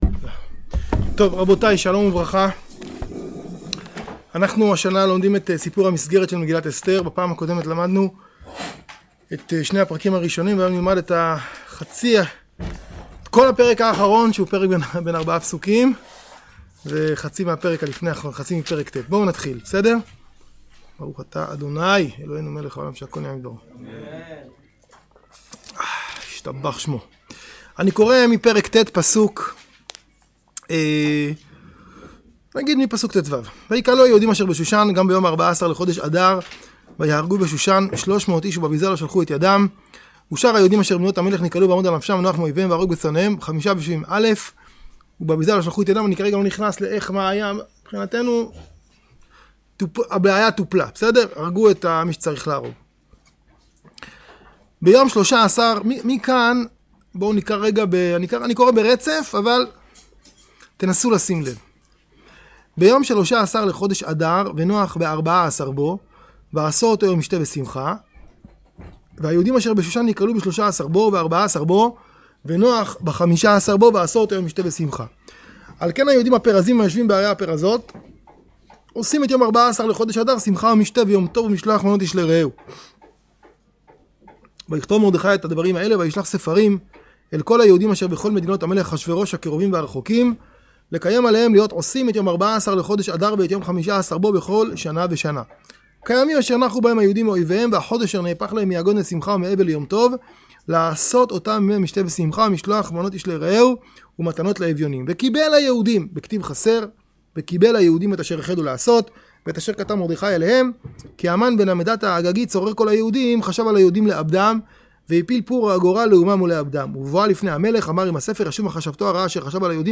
שיעורים בסיפורי אגדות חז"ל